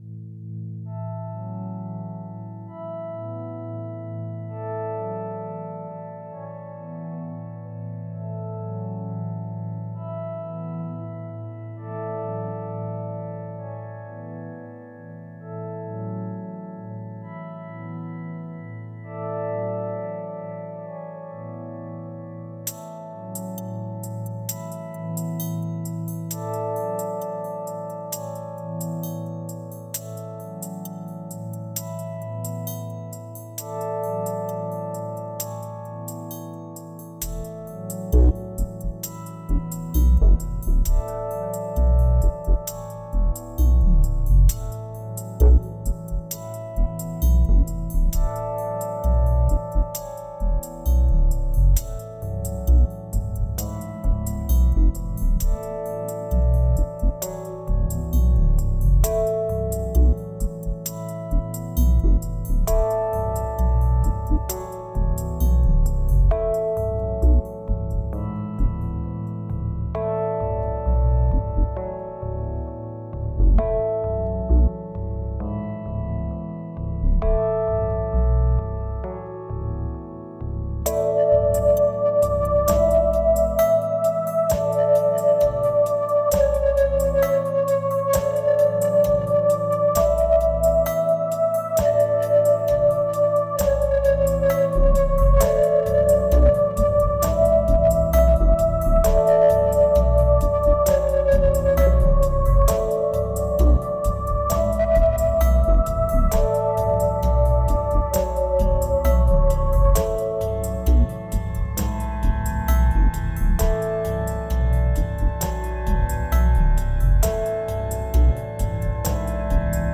2202📈 - 27%🤔 - 66BPM🔊 - 2012-11-05📅 - -30🌟